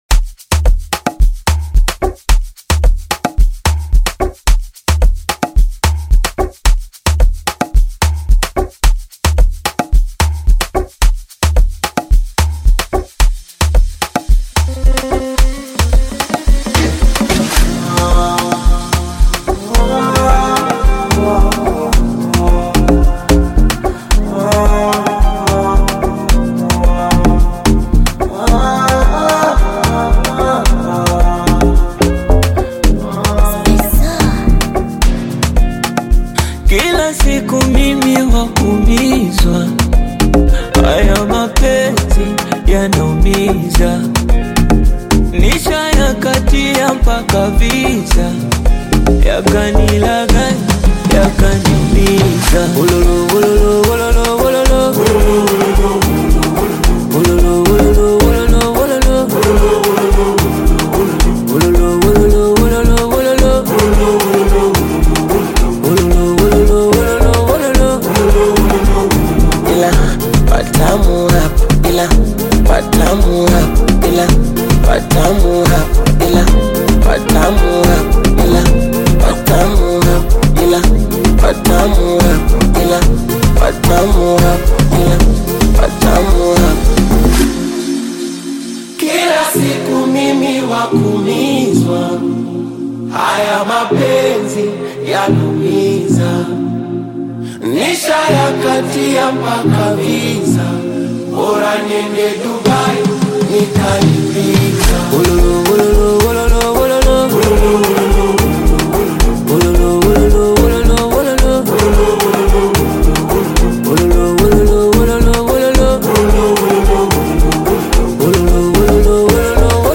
Tanzanian bongo flava music group consists of two members
Bongopiano song